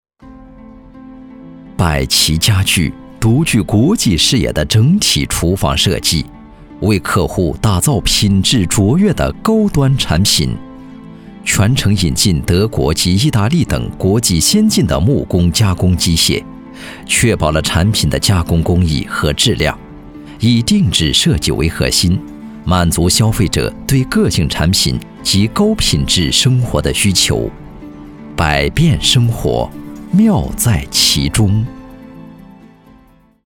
• 男S390 国语 男声 宣传片-百琪家具-广告宣传-深沉浑厚 大气浑厚磁性|沉稳|娓娓道来